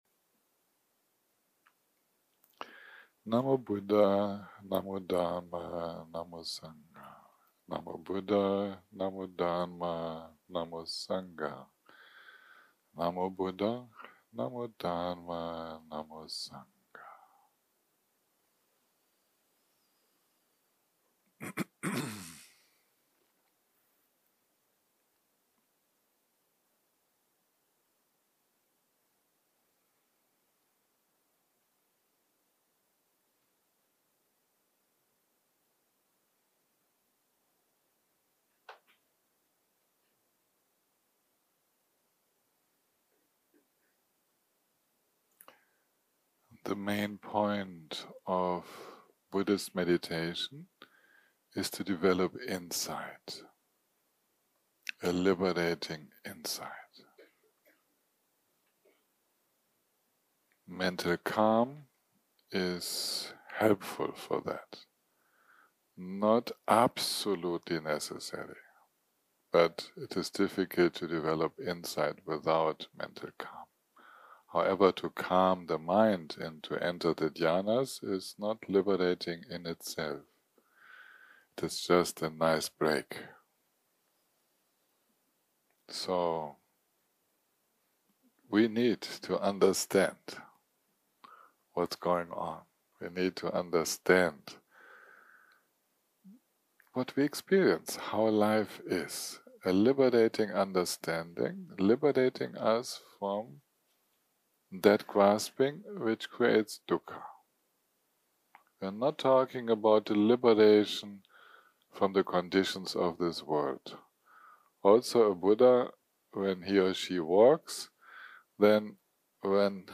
יום 4 - הקלטה 15 - בוקר - שיחת דהרמה - Developing Insight